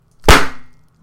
狙击手射击 重新装弹
描述：士兵们训练狙击手射击。带消音器（压制器）的射击，重新装弹的咔嚓声，最后有一个金属板目标被击中。
标签： 军事 第一人称射击 狙击 士兵 射击 武器 战争 侦察兵 实弹 消音器 沉默的 狙击手 战争 FPS 攻击 军队 定位技术 射击 杀死 射击
声道立体声